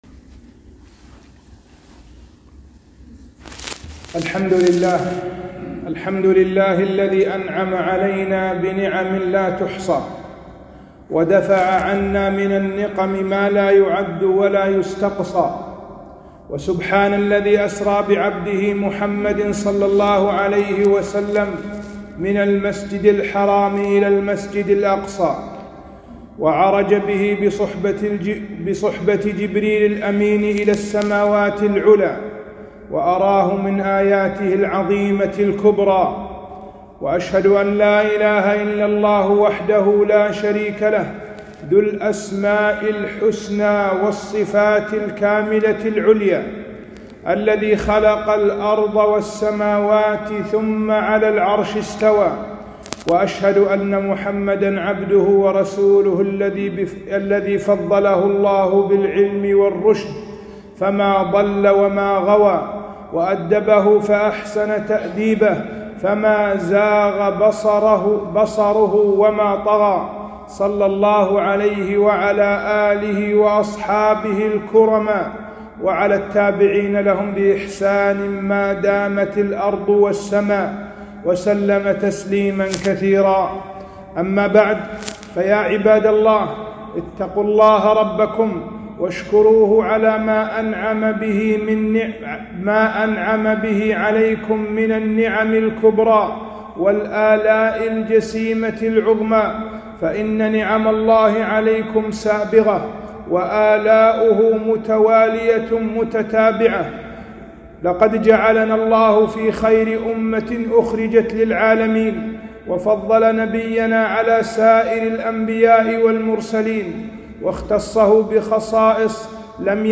خطبة - الإسراء والمعراج من معجزات النبوّه